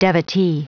Prononciation du mot devotee en anglais (fichier audio)
Prononciation du mot : devotee